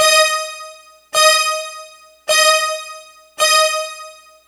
Synth Lick 50-07.wav